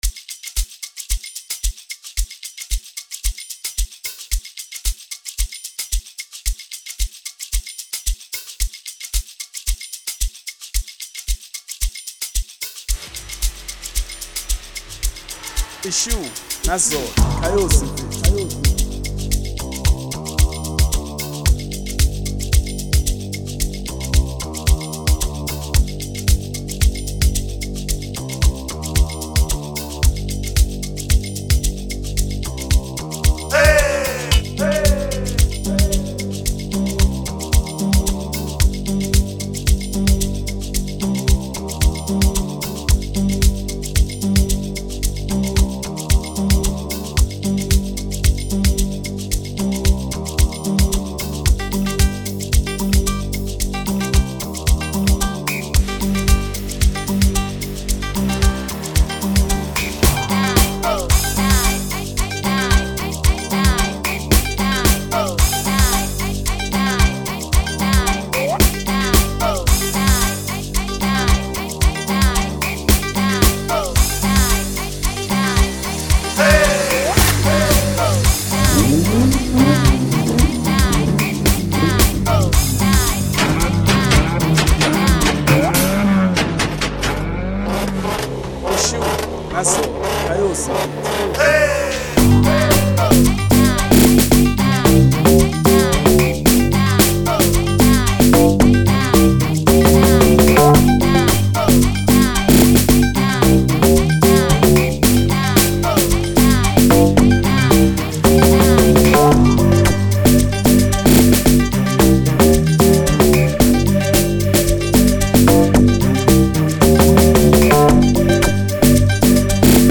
04:25 Genre : Amapiano Size